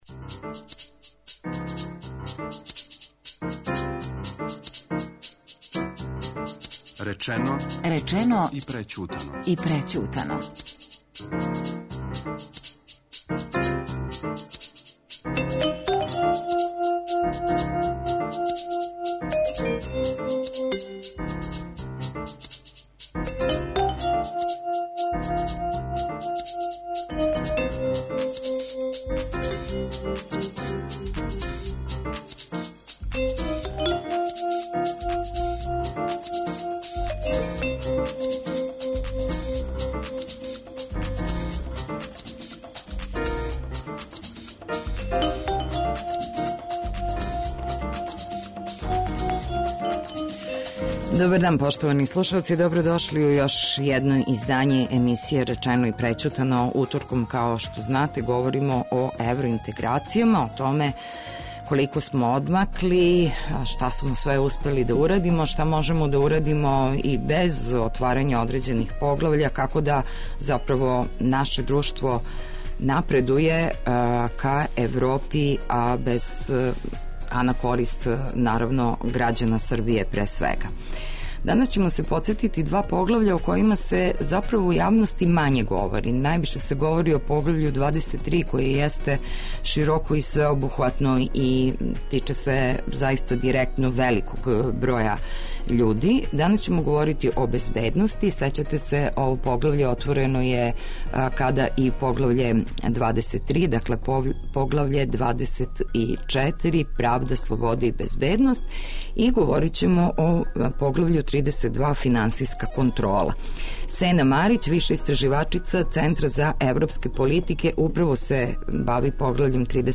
Гости у студију су